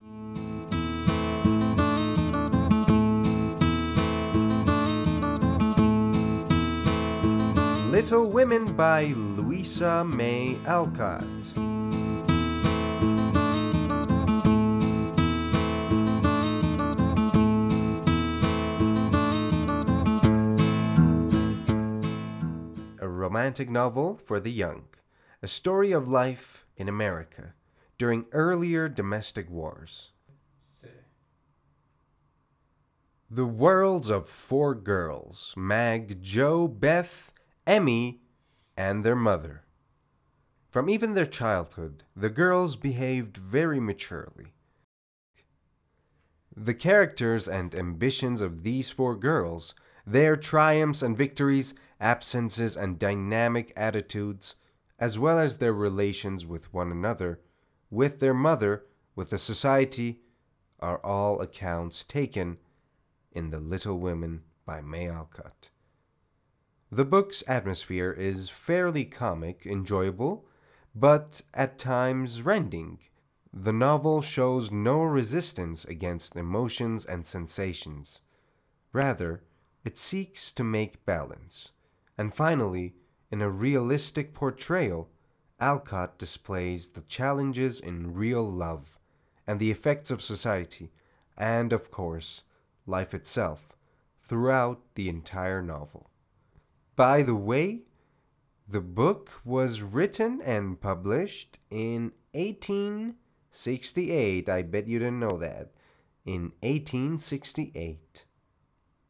معرفی صوتی کتاب Little Women